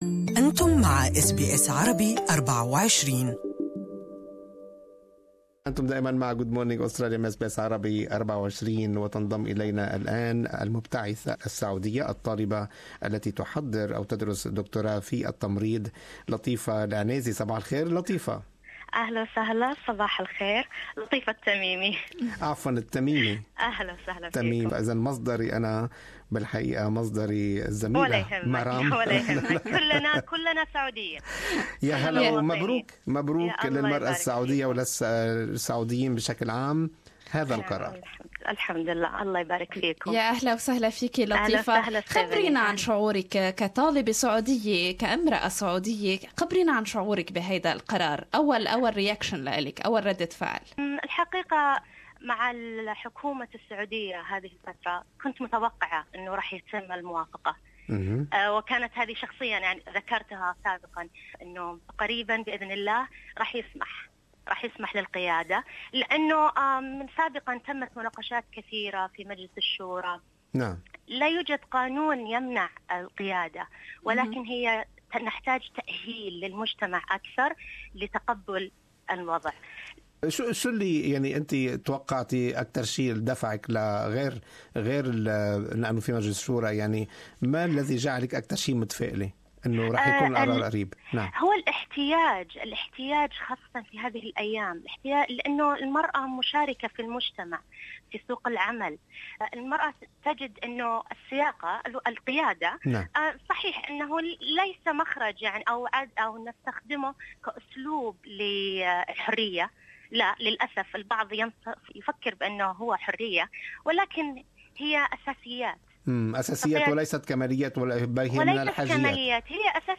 لسماع اللقاء الكامل يمكنكم الضغط على التدوين الصوتي أعلاه.